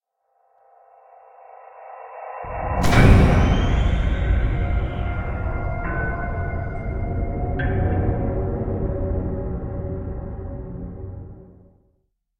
Minecraft Version Minecraft Version snapshot Latest Release | Latest Snapshot snapshot / assets / minecraft / sounds / ambient / cave / cave17.ogg Compare With Compare With Latest Release | Latest Snapshot
cave17.ogg